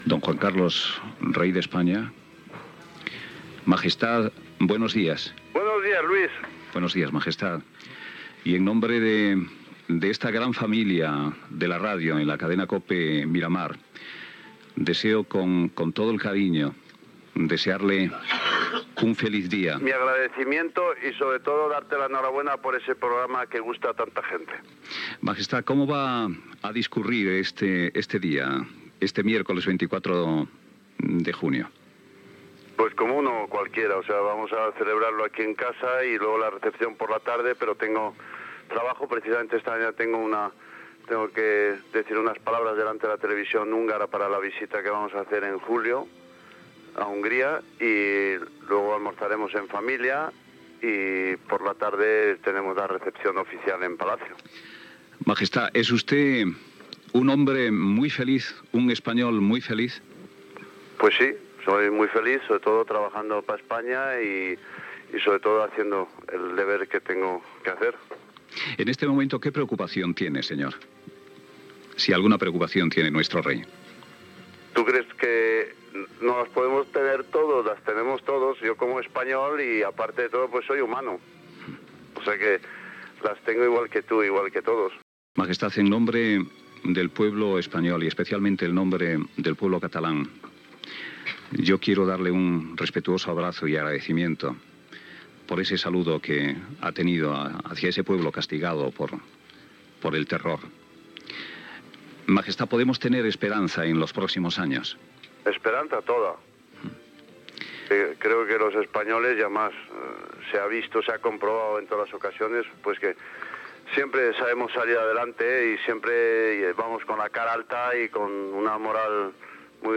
Felicitació telefònica al Rei Juan Carlos I amb motiu del seu aniversari i entrevista d'actualitat
Info-entreteniment